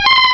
-Replaced the Gen. 1 to 3 cries with BW2 rips.
mareep.aif